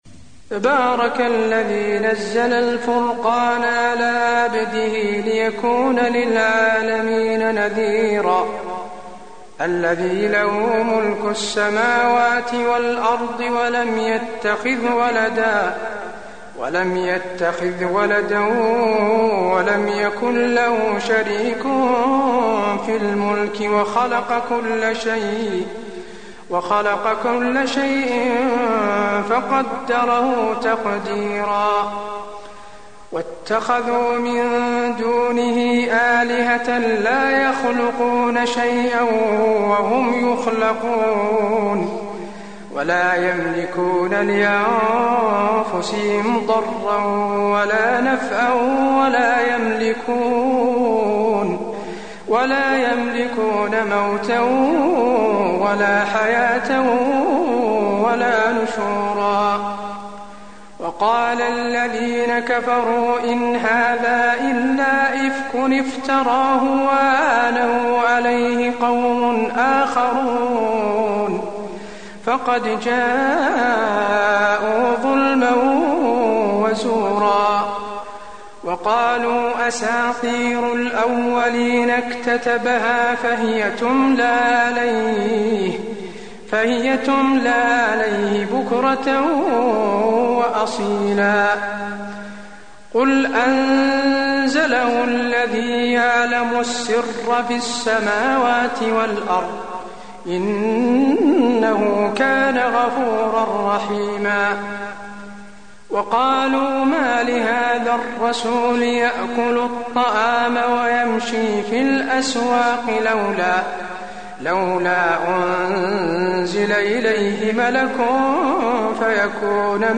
المكان: المسجد النبوي الفرقان